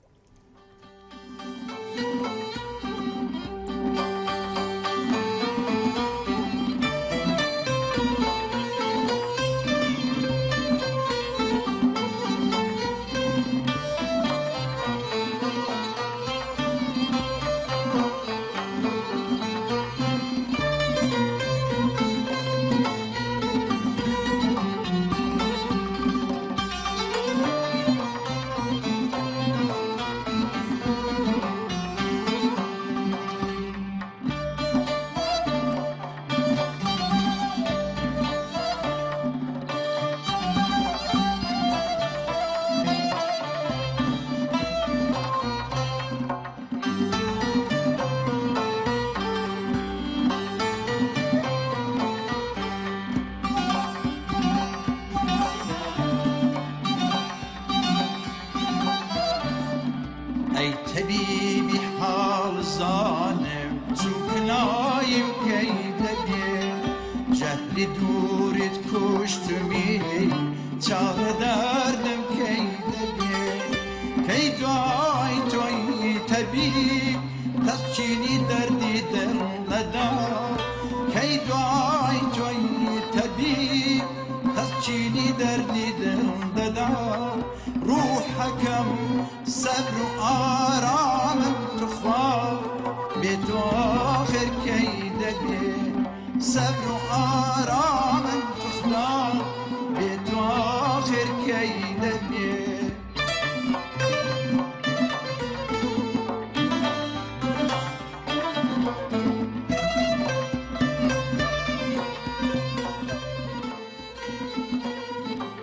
گورانی کوردی حاڵی زارم